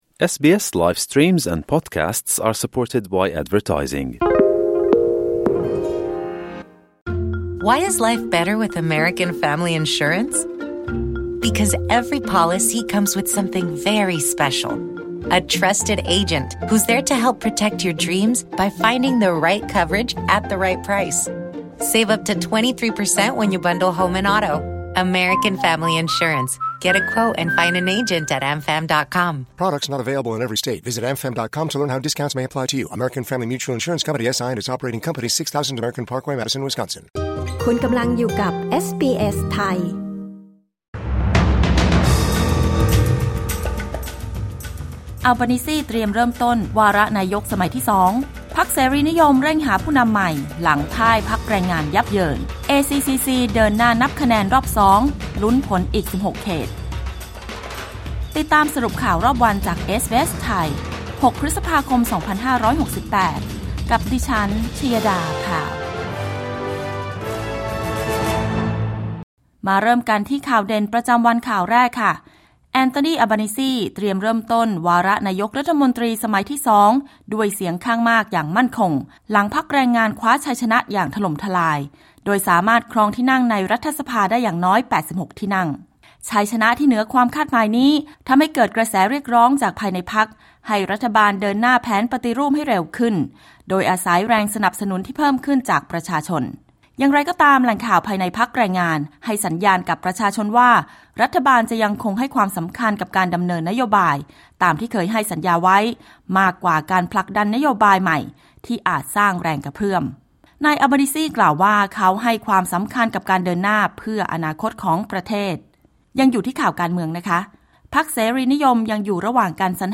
สรุปข่าวรอบวัน 6 พฤษภาคม 2568